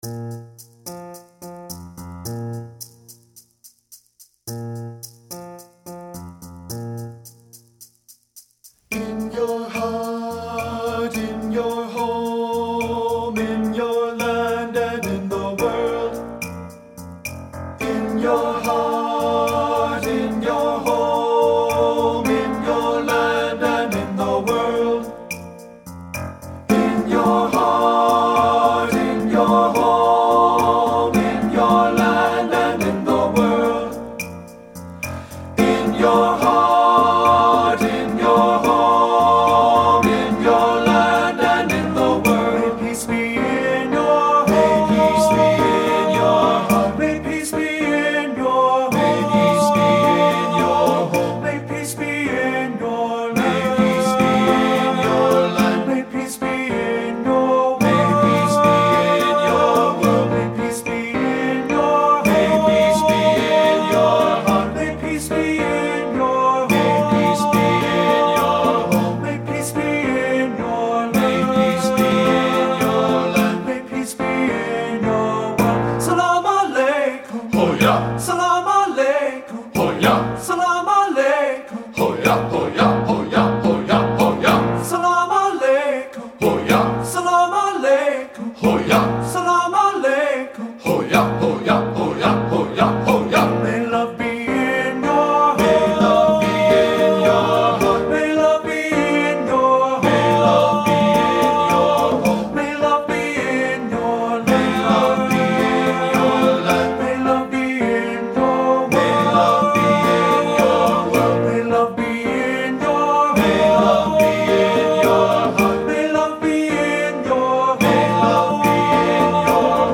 • Tenor
• Bass
Ensemble: Unison and Two-Part Chorus
Accompanied: A cappella